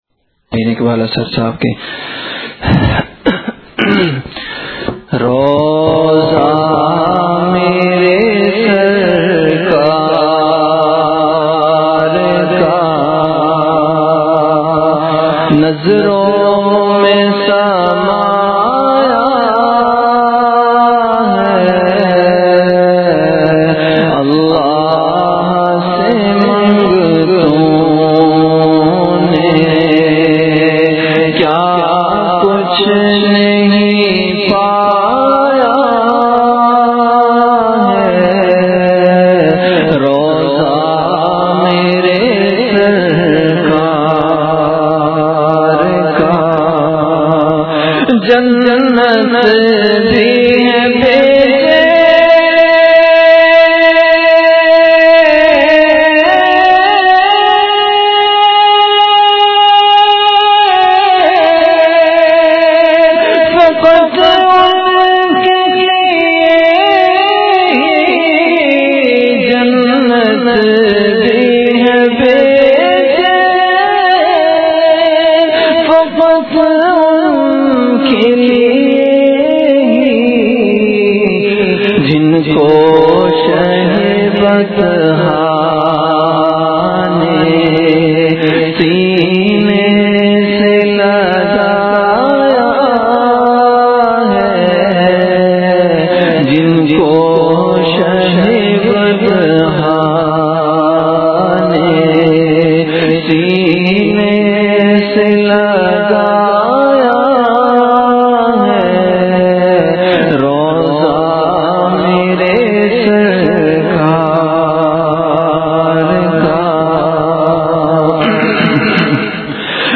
Ashaar · Khanqah Imdadia Ashrafia
CategoryAshaar
Event / TimeAfter Isha Prayer